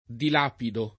dilapido [ dil # pido ]